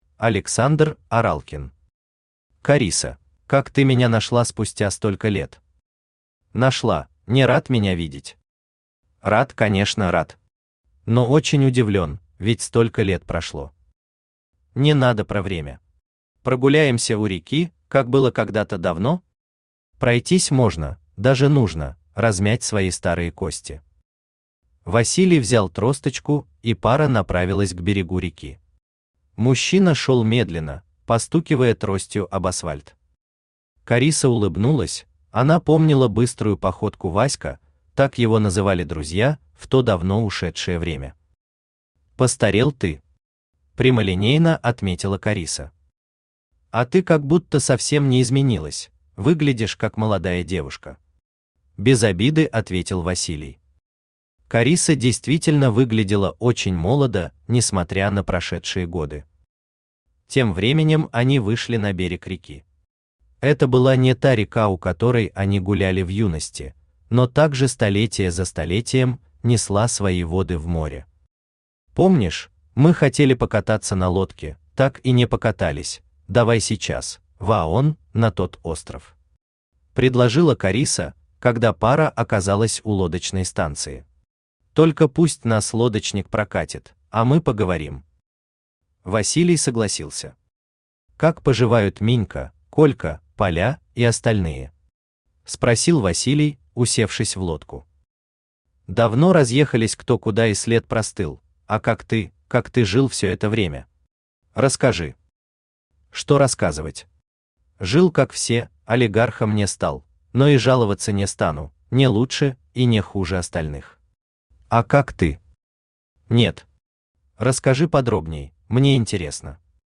Аудиокнига Карисса | Библиотека аудиокниг
Aудиокнига Карисса Автор Александр Аралкин Читает аудиокнигу Авточтец ЛитРес.